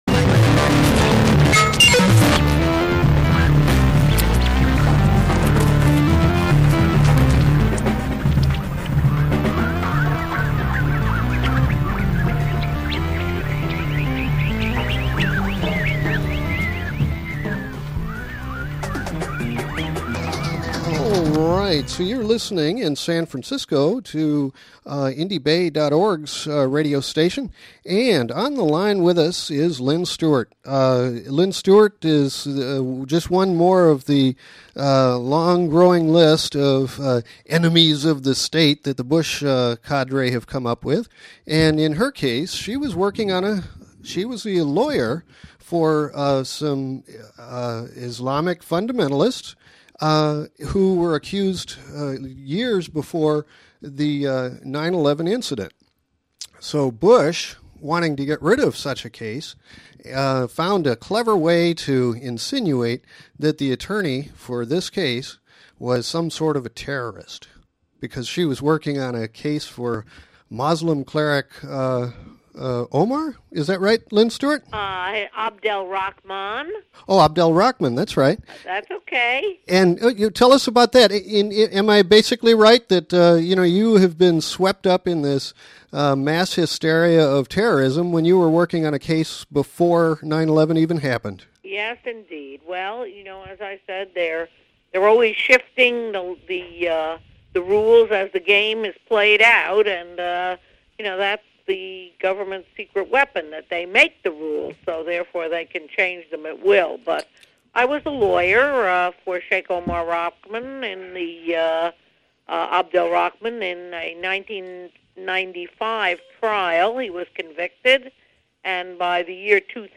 Interview with Attorney Lynne Stewart, accused by the Bush administration of aiding terrorists.